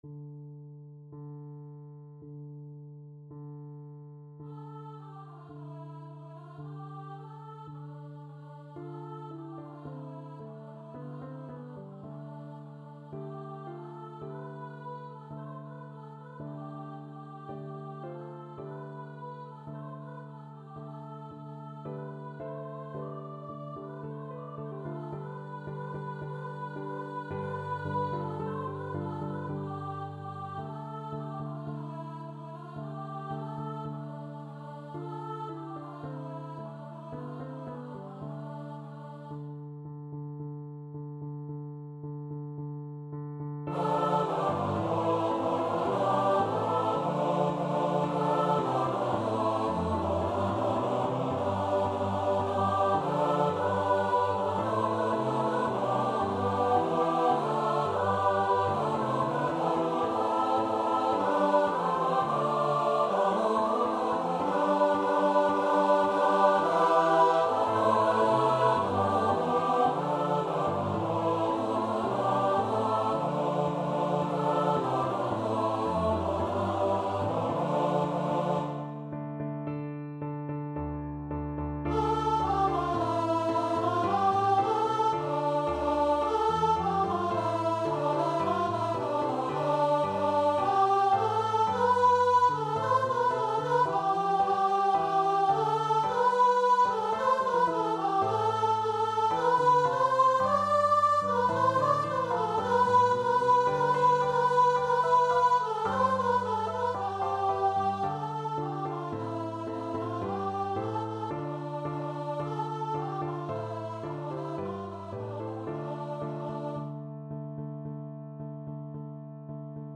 Free Sheet music for Choir (SATB)
SopranoAltoTenorBass
C major (Sounding Pitch) (View more C major Music for Choir )
Steady two in a bar =c.110
2/2 (View more 2/2 Music)
Classical (View more Classical Choir Music)